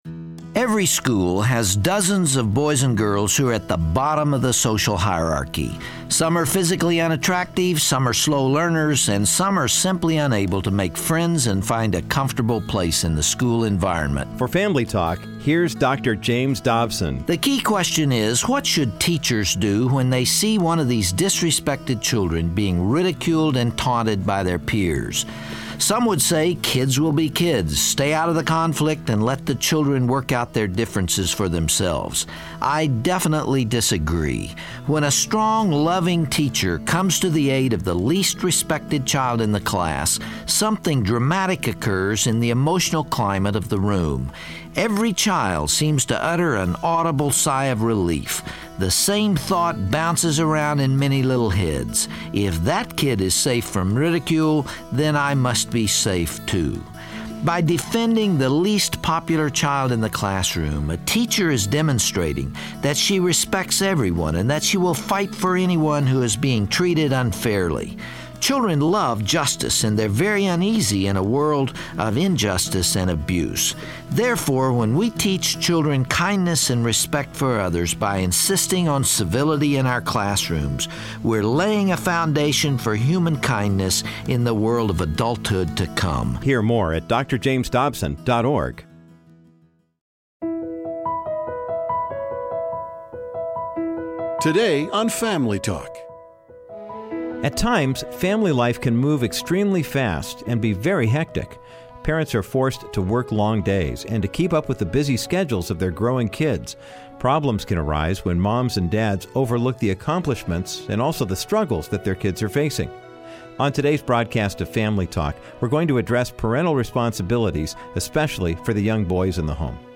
On todays Family Talk broadcast, you will hear the remainder of Dr. Dobsons speech from the Bringing Up Boys DVD series. He implores moms and dads to stay attentive to the needs of their kids by surrounding them with love and respect. Parents in the audience also asked Dr. Dobson how they should talk to their kids about purity, decency, confidence, and attitude problems.